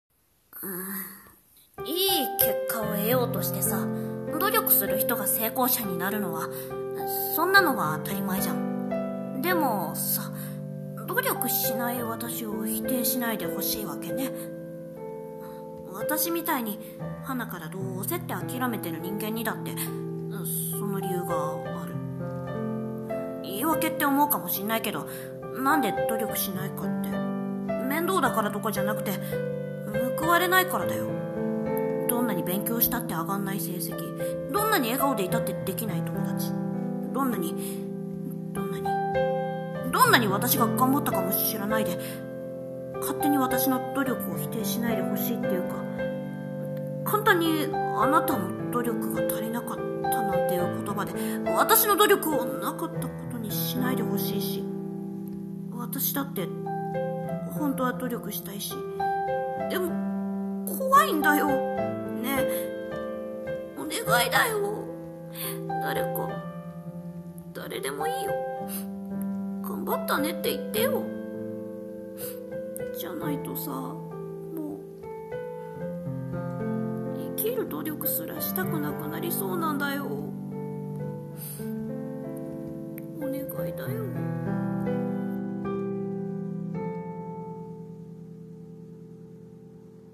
声劇 : 努力の話